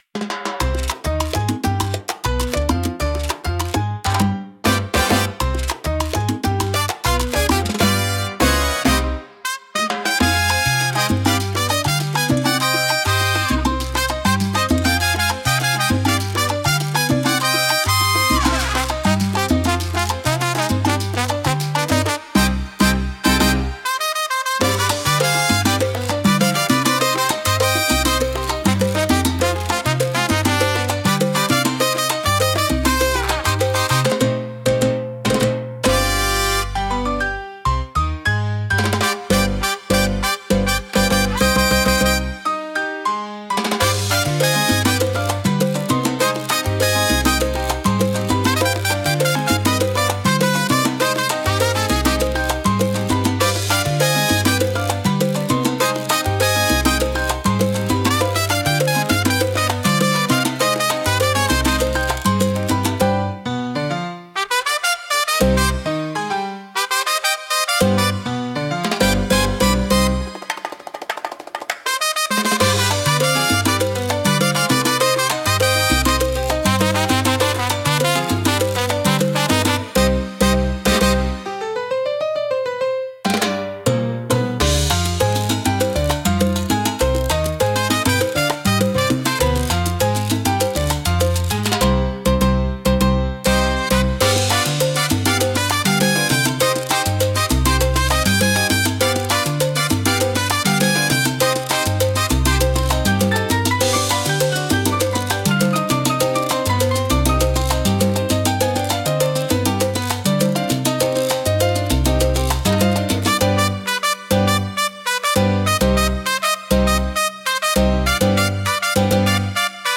聴く人に活力と情熱を与え、明るく熱気に満ちた空間を作り出します。